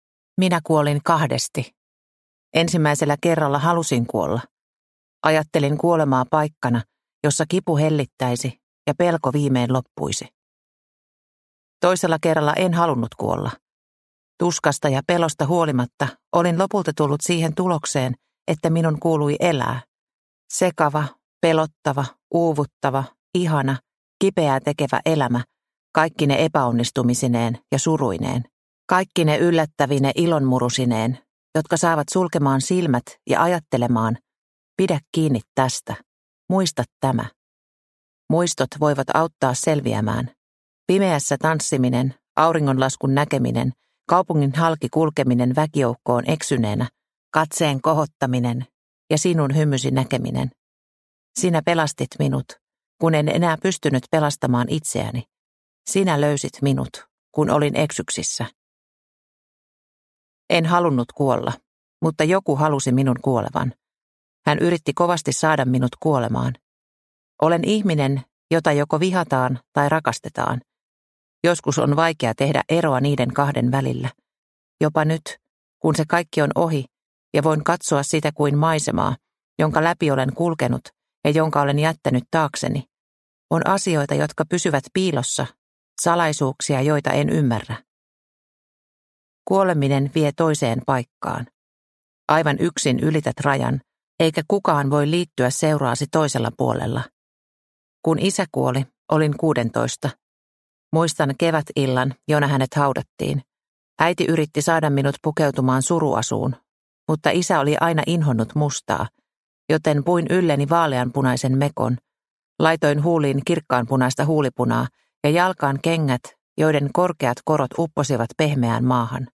Syöksykierre – Ljudbok – Laddas ner